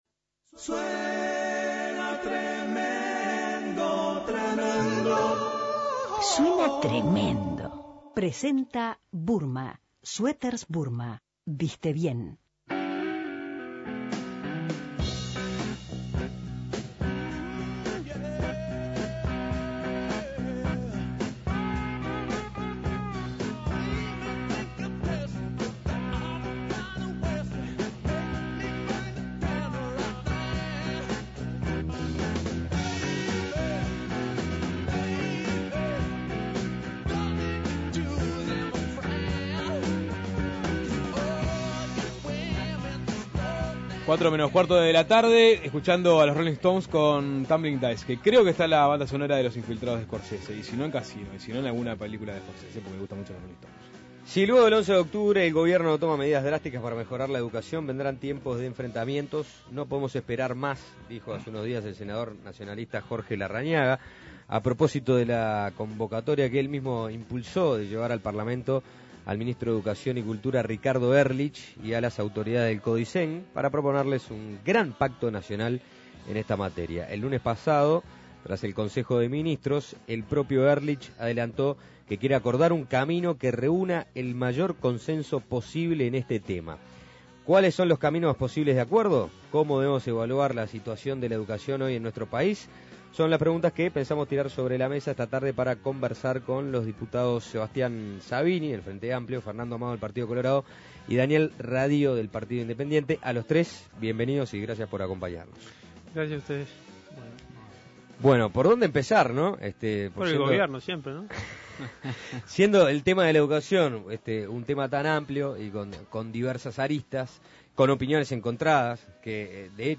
La educación en debate
¿Cómo debemos evaluar la situación de la Educación hoy en nuestro país? Son las preguntas que tiramos sobre la mesa con los diputados Sebastián Sabini (FA), Fernando Amado (PC) y Daniel Radío (PI).